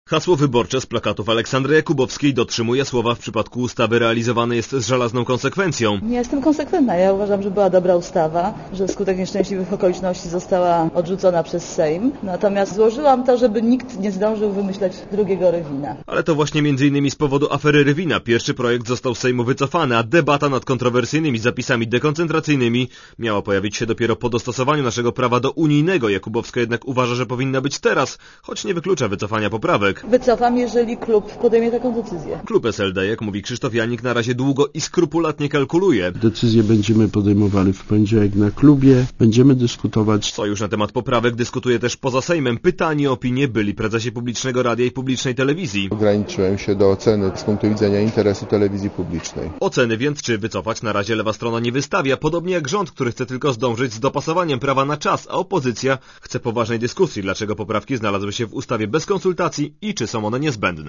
Posłuchaj materiału reportera Radia Zet